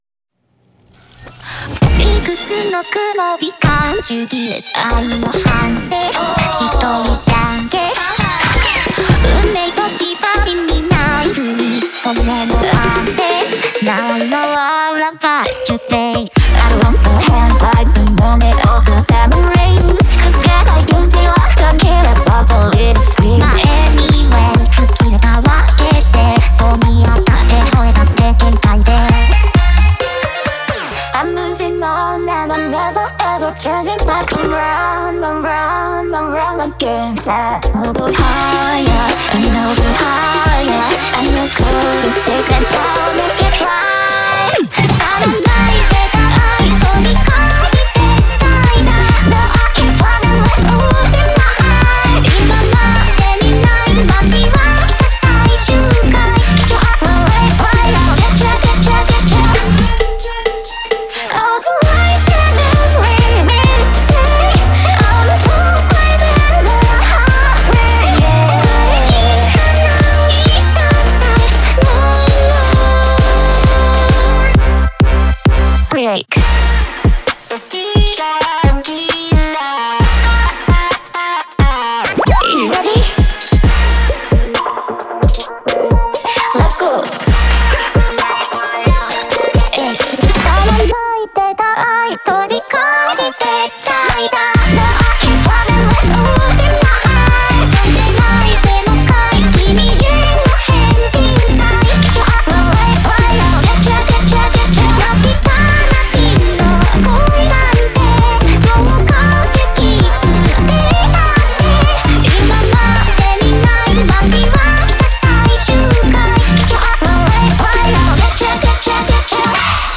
BPM66-132
Audio QualityMusic Cut